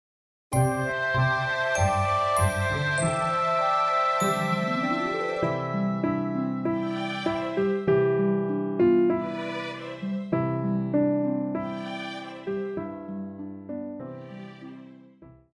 古典
鋼琴
樂團
聖誕歌曲,傳統歌曲／民謠,聖歌,教會音樂,古典音樂
鋼琴曲,演奏曲
獨奏與伴奏
有節拍器